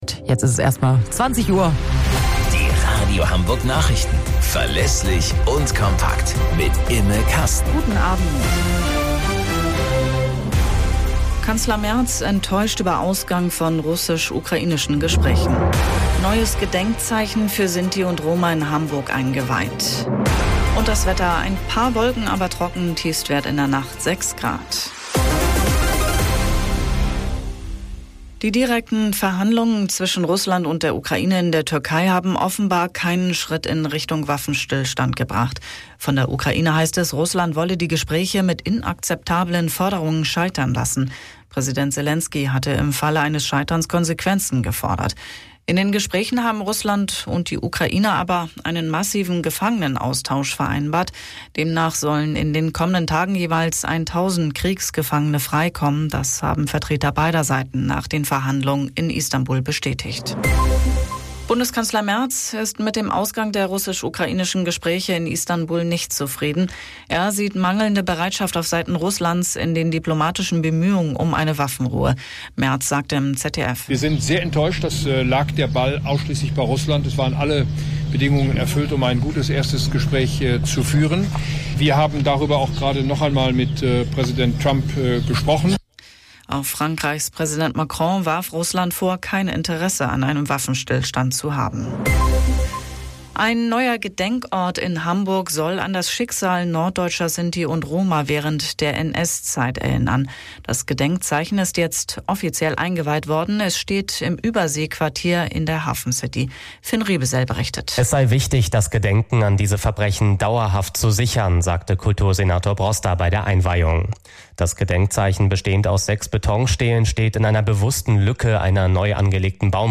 Radio Hamburg Nachrichten vom 17.05.2025 um 03 Uhr - 17.05.2025